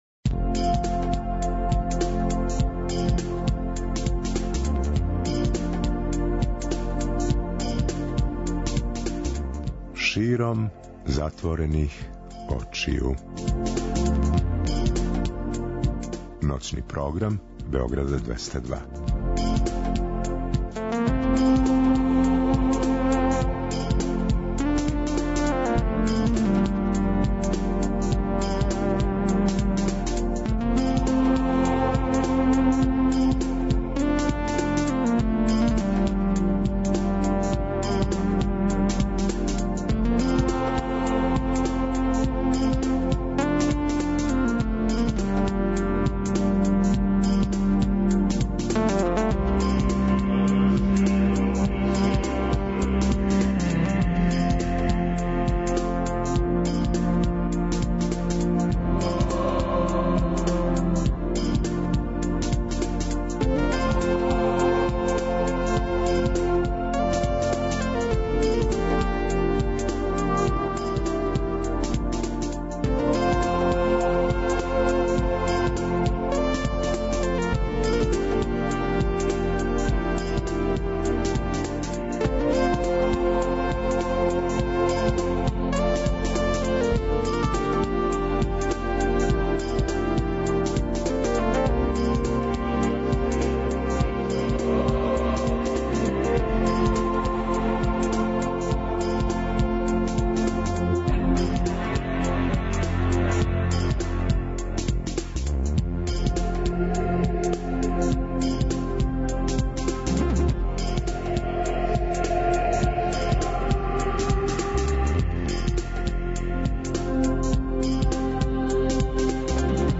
Широм затворених очију, ново издање емисије Златне године, музика 60-тих и 70-тих, када је музика хтела да промени свет, а свет је променио музику.